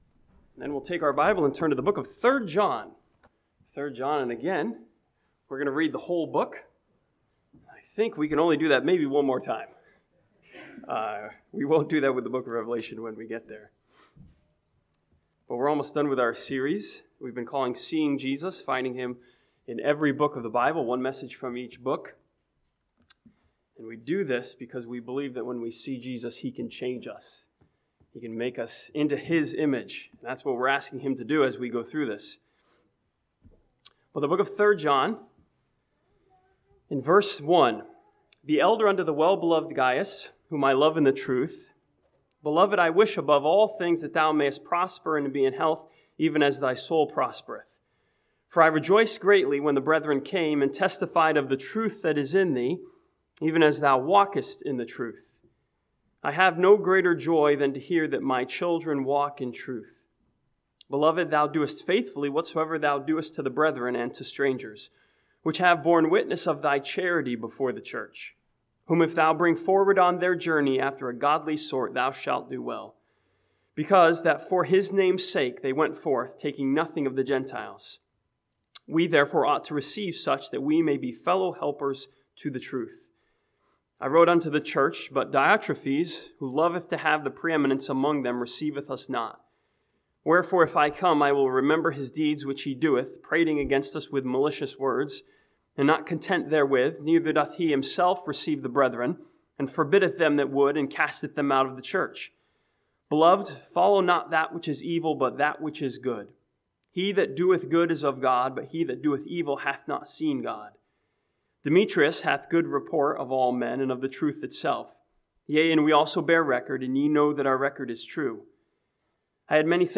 This sermon from 3 John sees Jesus as our Helper who has helped bring us forward on our journey.